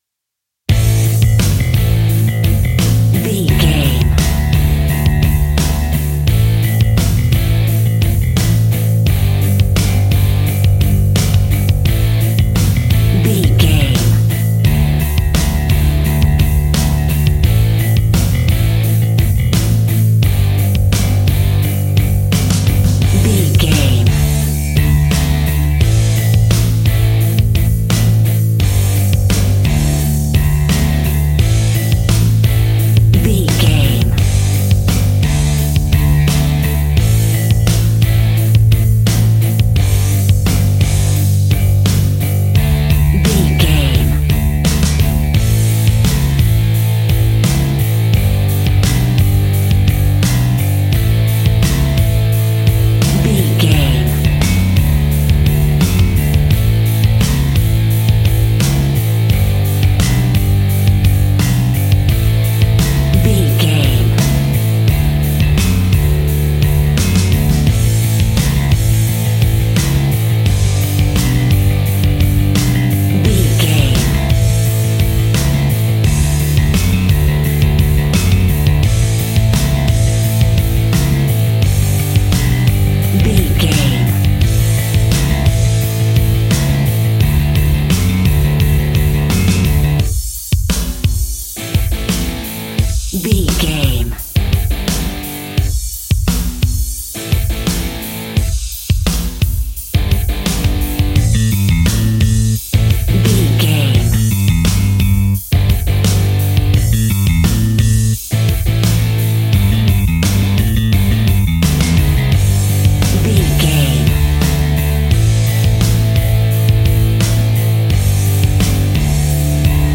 Epic / Action
Fast paced
Aeolian/Minor
hard rock
blues rock
distortion
rock instrumentals
Rock Bass
heavy drums
distorted guitars
hammond organ